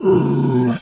bear_roar_soft_14.wav